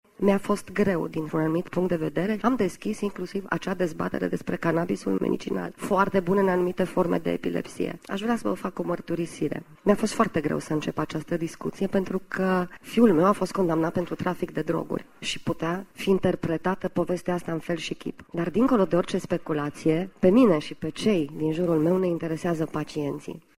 Ministrul Sănătăţii, Sorina Pintea afirmă că trebuie să se vorbească deschis despre această afecţiune şi a menţionat că a demarat discuţiile despre utilizarea canabisului în tratamentele medicale, cu toate că nu i-a fost uşor să abordeze acest subiect: